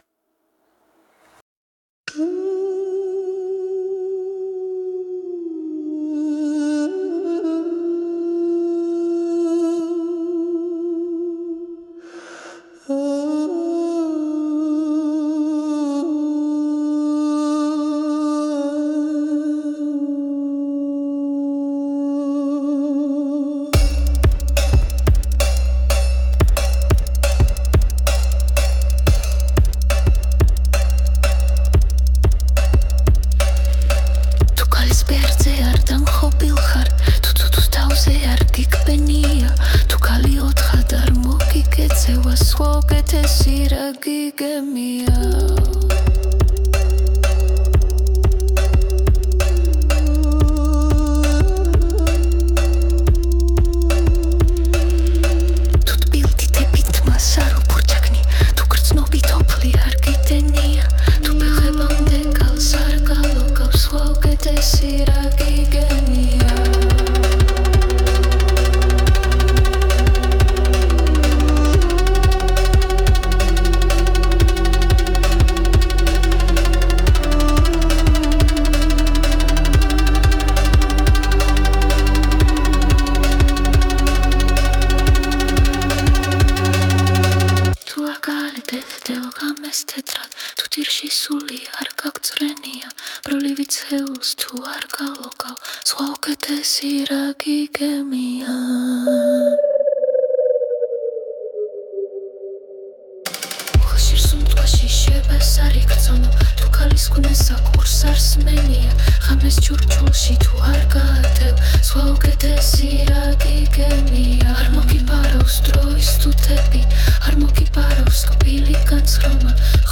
шёпот шахерезады) впечатляет. переименуй как-нибудь, что ли